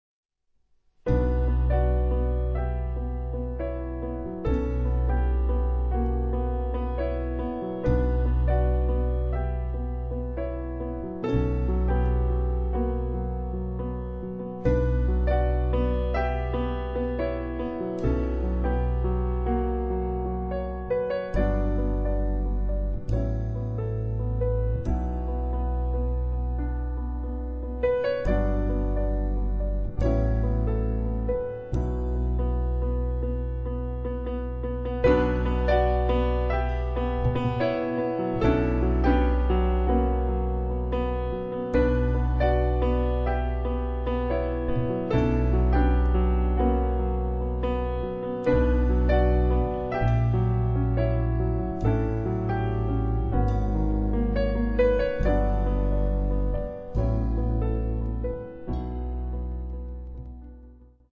bass
drums
piano
trombone
trumpet
soprano sax